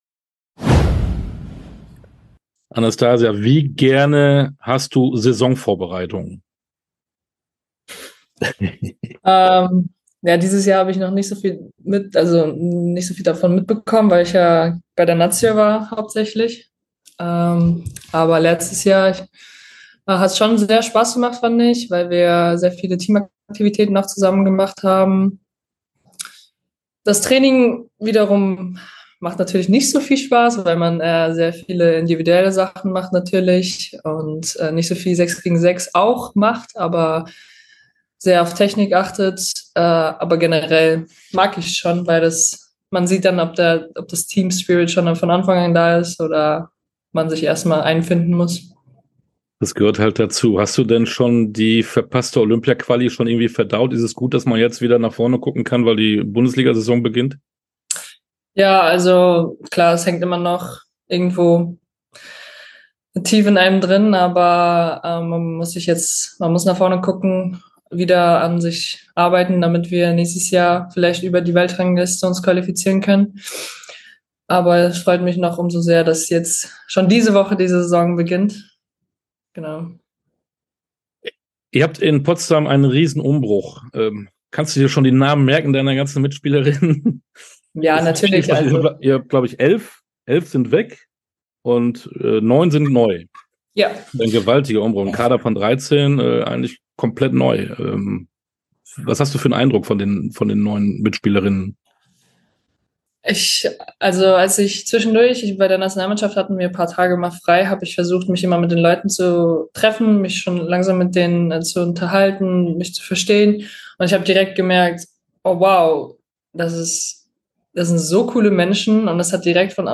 Sportstunde - Interviews in voller Länge Podcast